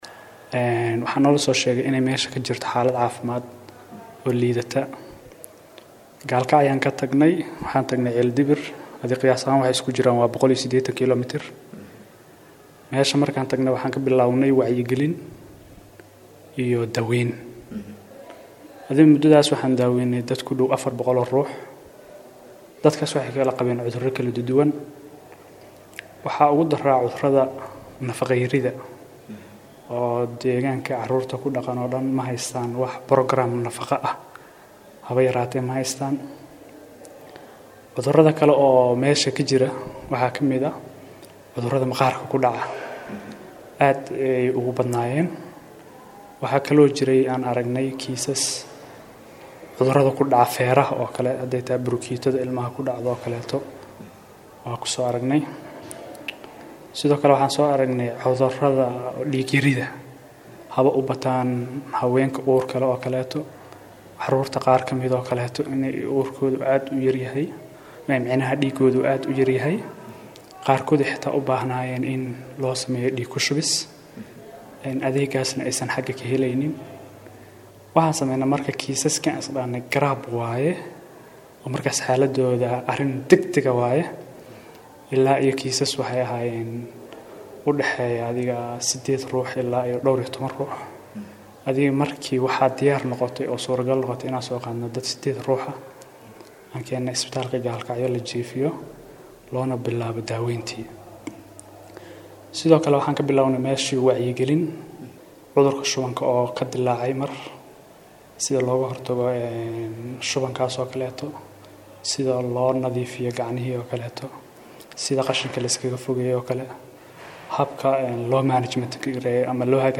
Wareysi-adeeg-caafimaad-ok-Mudug.mp3